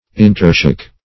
Intershock \In`ter*shock\, v. t. To shock mutually.